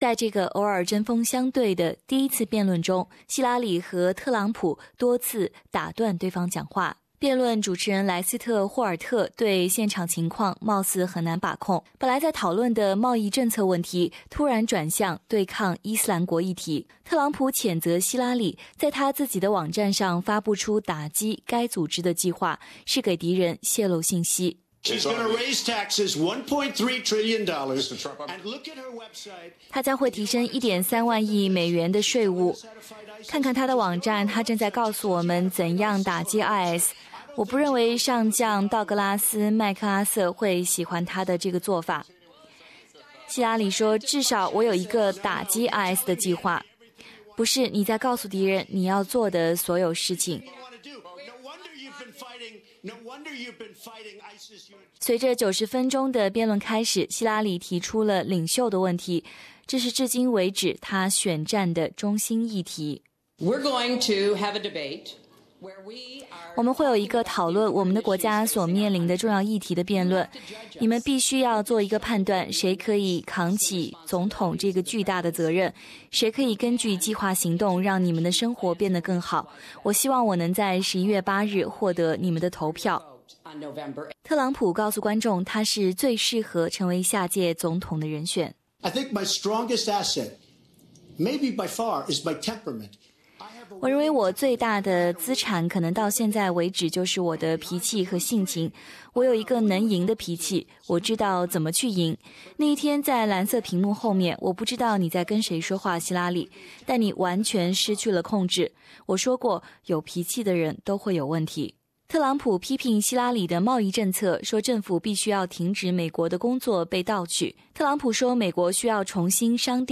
Democrat Hillary Clinton (R) and Republican Donald Trump at the end of the first Presidential Debate at Hofstra University in Hempstead, Source: AAP